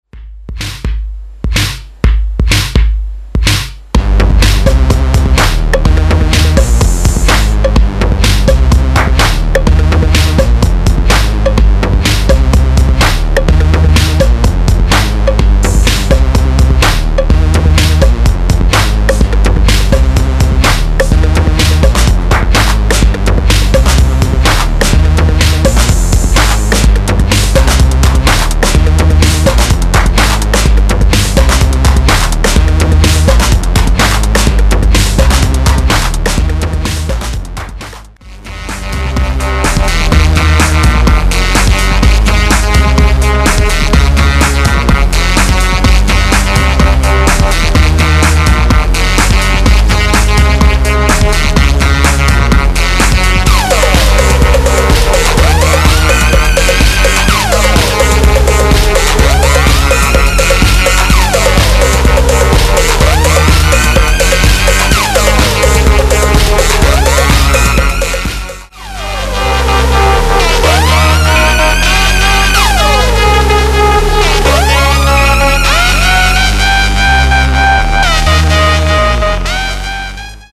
a brooding monster of a tune